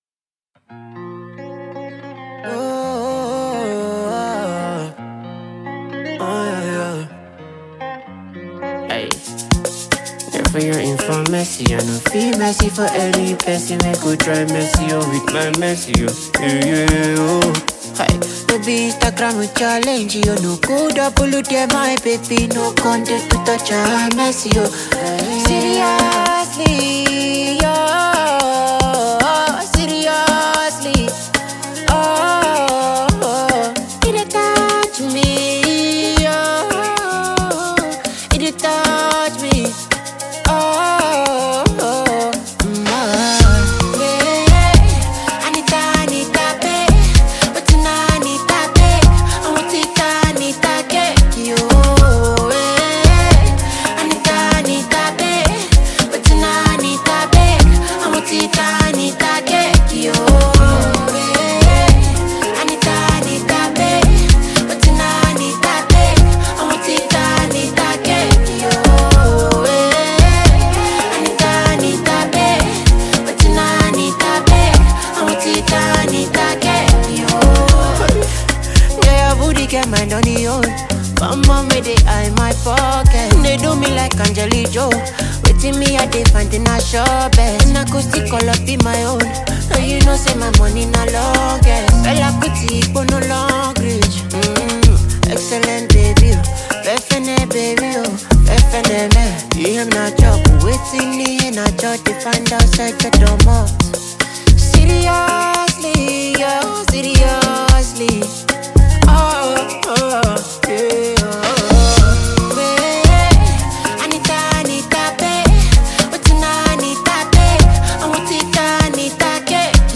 Nigerian / African Music
Genre: Afrobeats